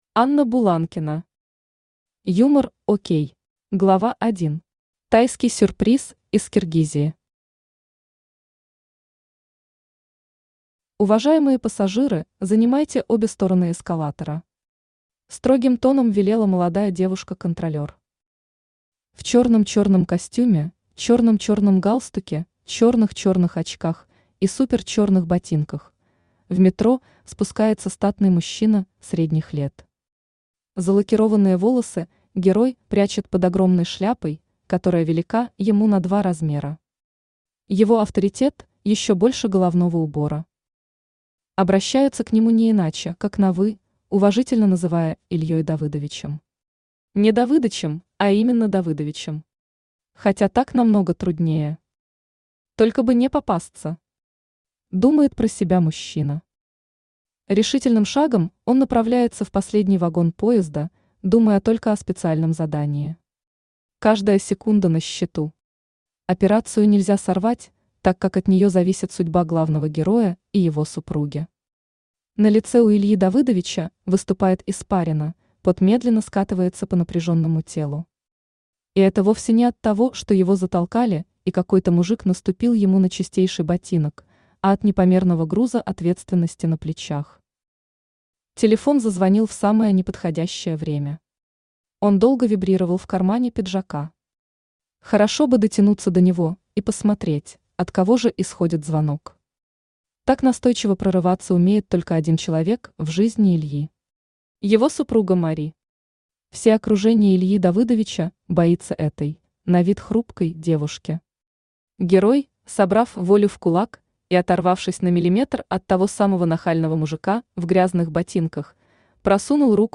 Аудиокнига ЮморОк | Библиотека аудиокниг
Aудиокнига ЮморОк Автор Анна Сергеевна Буланкина Читает аудиокнигу Авточтец ЛитРес.